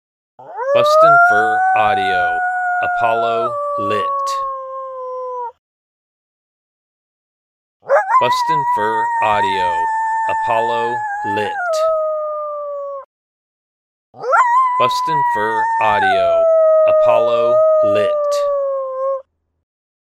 Alpha male Coyote, Apollo, howling in response to a lone howl. Excellent follow up howl to use on stand to get the wild coyotes fired up.